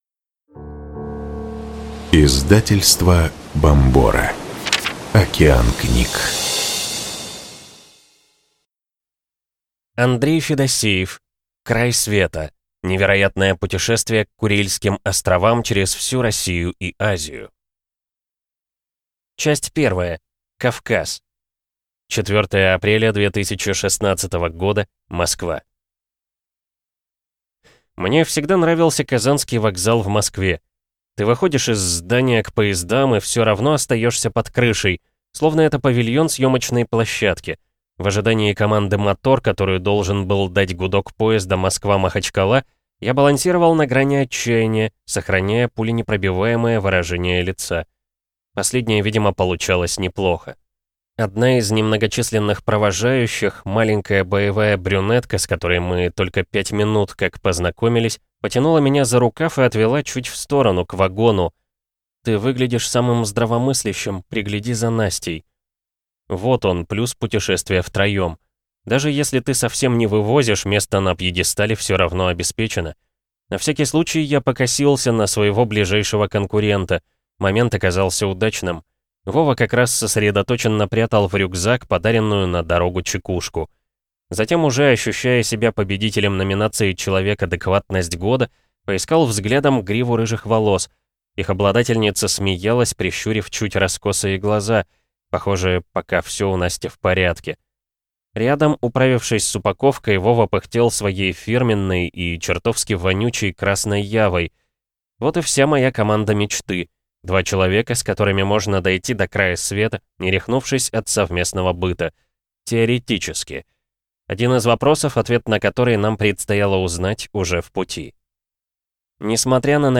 Аудиокнига Край Света. Невероятное путешествие к Курильским островам через всю Россию и Азию | Библиотека аудиокниг